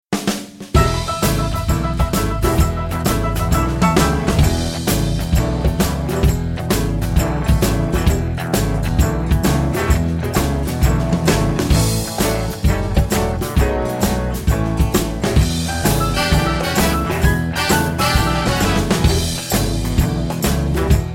instrumental accompaniment music